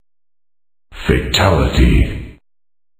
Fatality-sound-HIingtone
fatality_26320.mp3